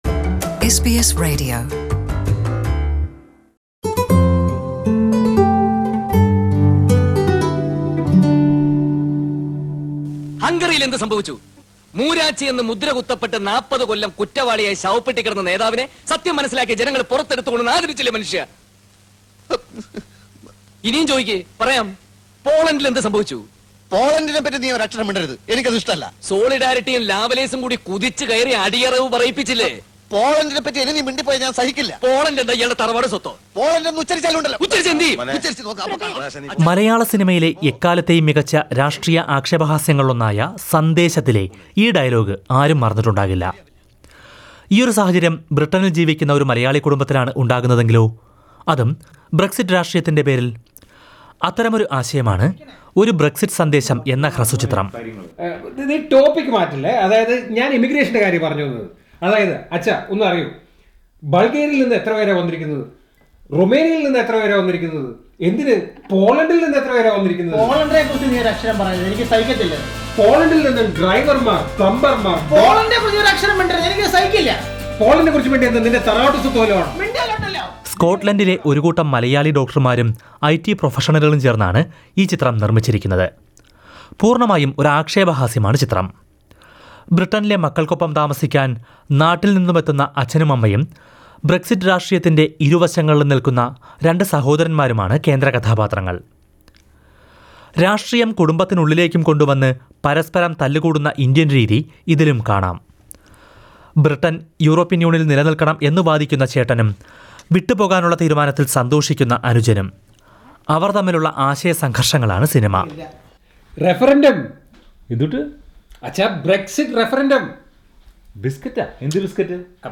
Listen to a report on the Malayalam short film by a group of British Malayalees on Brexit -one of the most discussed topic.